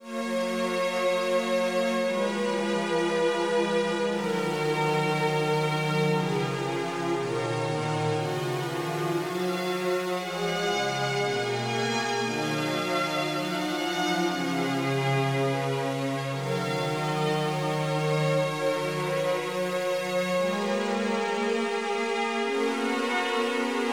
Synth Strings + Warm